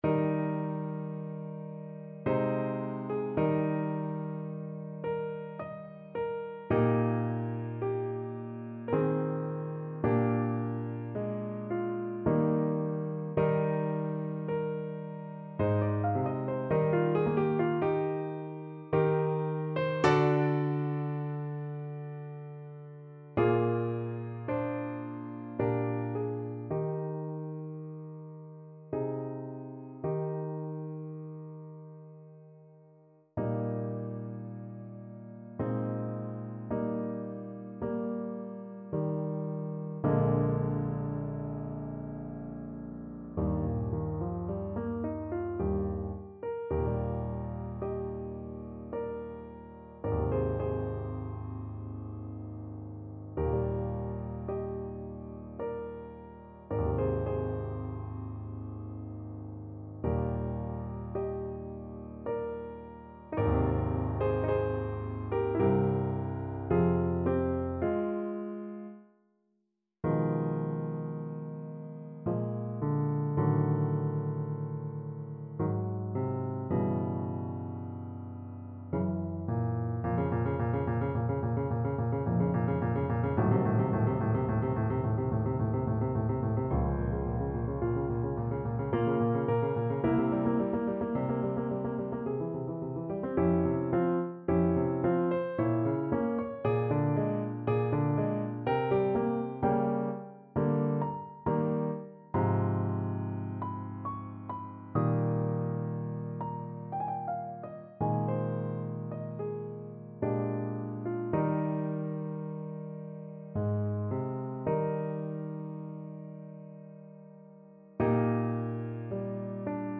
Play (or use space bar on your keyboard) Pause Music Playalong - Piano Accompaniment Playalong Band Accompaniment not yet available transpose reset tempo print settings full screen
Adagio ma non troppo =108
Eb major (Sounding Pitch) (View more Eb major Music for Trombone )